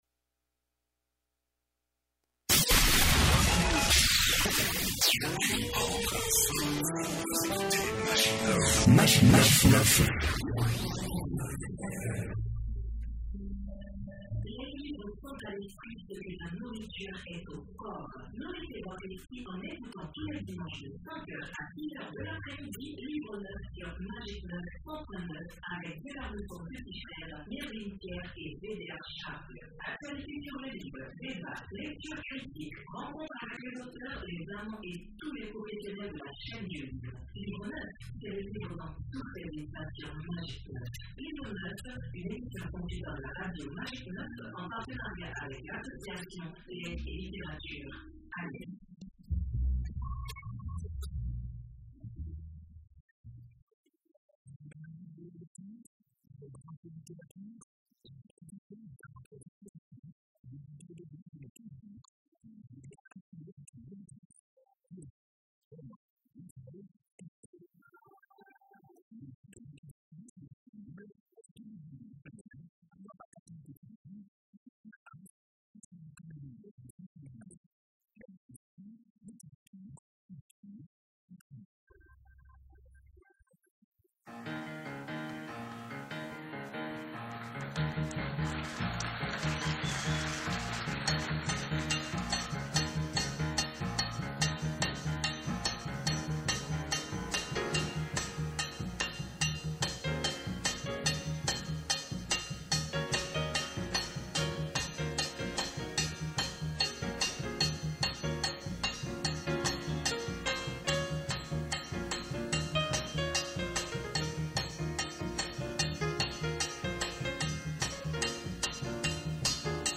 Pour en parler, l’Association Legs et Littérature (ALEL) et la radio Magik 9, 100.9, reçoivent ce dimanche 24 septembre 2017